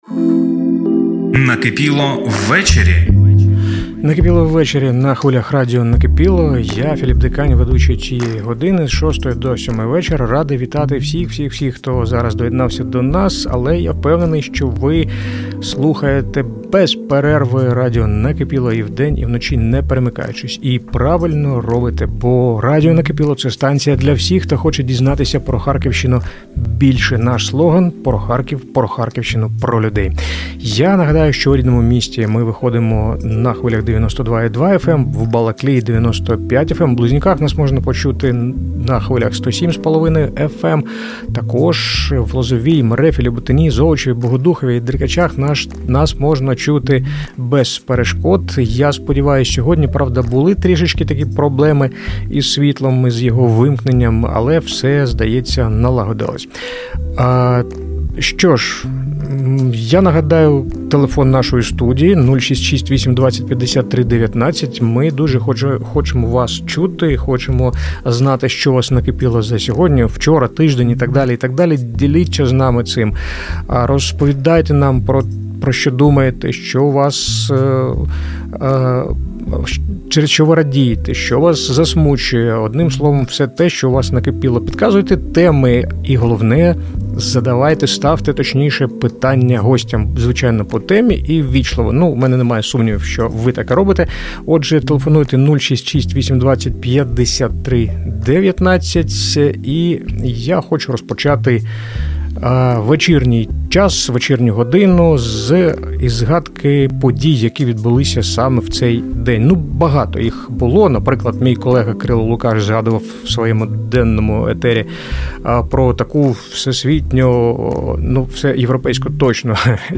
Інтерв’ю
Вийшов змістовний і цікавий діалог про актуальні питання сьогодення. Пропонуємо вам послухати аудіо запис цього етеру.